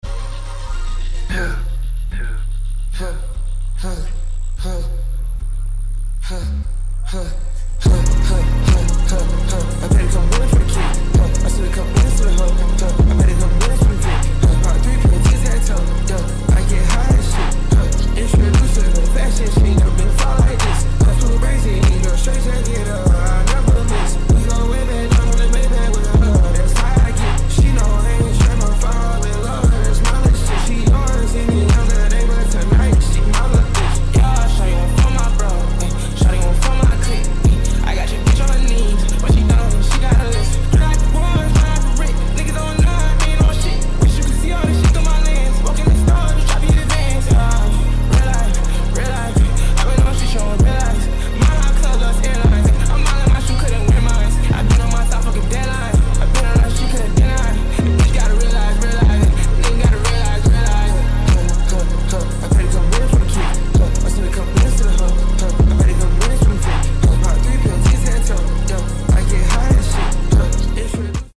reversed original instrumental and turns out it sounded nice